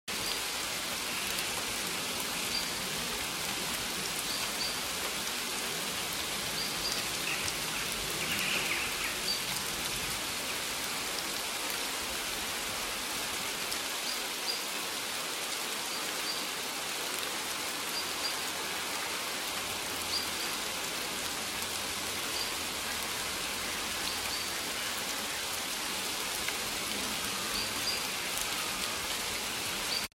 جلوه های صوتی
دانلود صدای پرنده 46 از ساعد نیوز با لینک مستقیم و کیفیت بالا